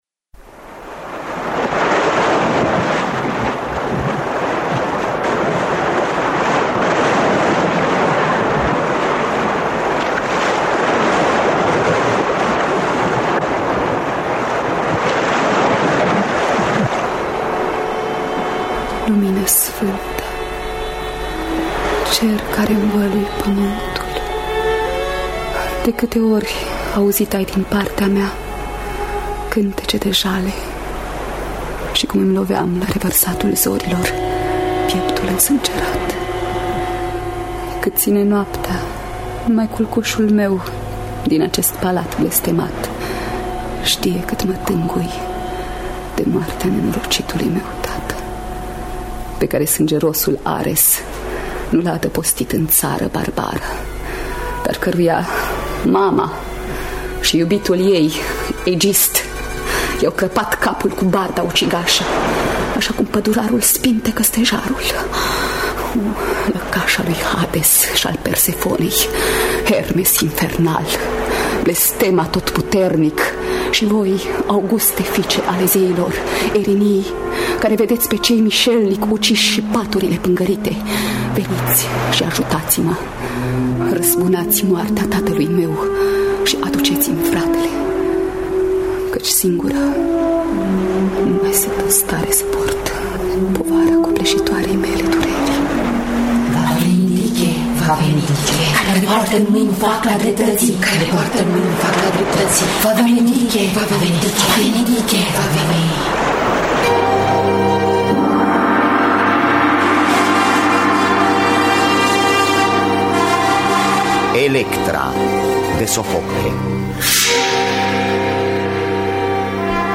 Electra de Sofocle – Teatru Radiofonic Online
Înregistrare din anul 1984.